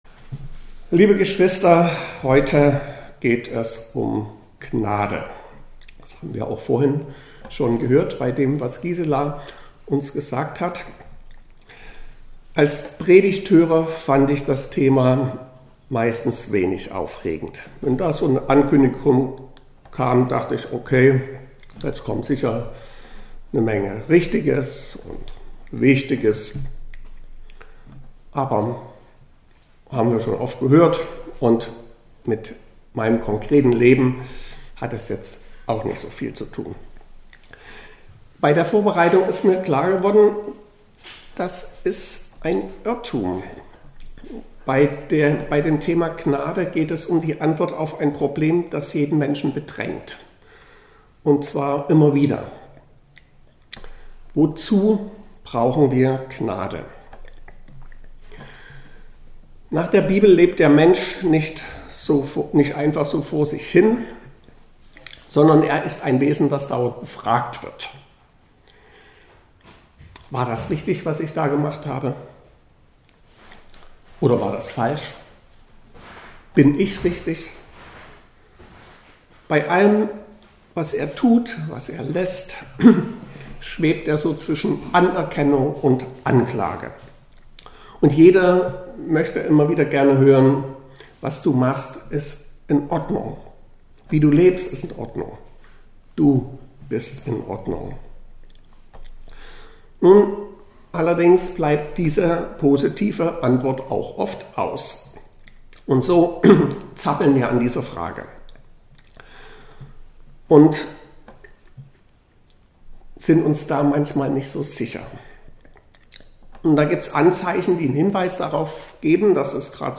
Predigten - Evangelisch-Freikirchliche Gemeinde Berlin Pankow (Niederschönhausen)